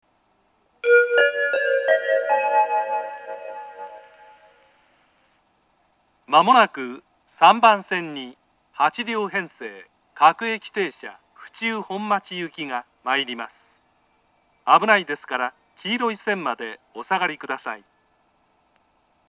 ３番線接近放送 各駅停車府中本町行の放送です。